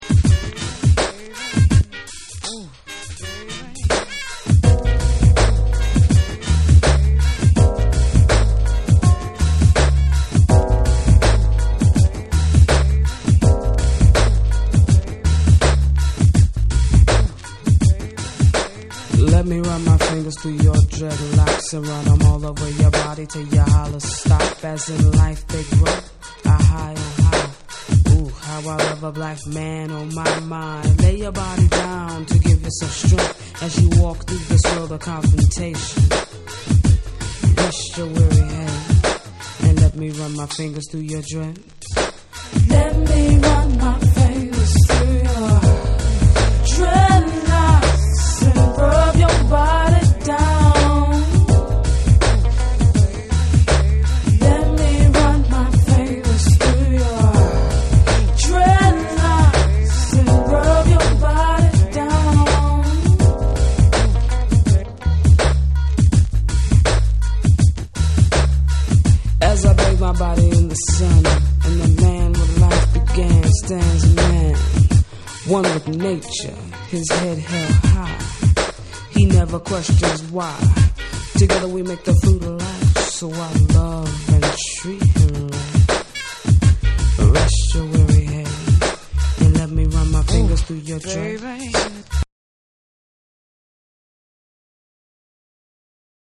ベーシスト&マルチ・プレイヤー
BREAKBEATS